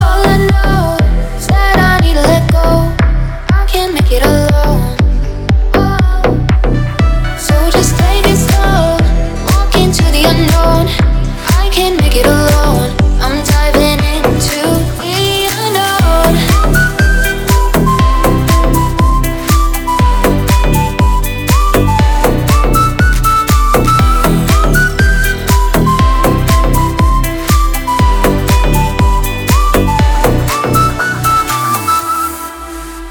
свист
deep house
зажигательные
EDM
красивый женский голос
Стиль: deep house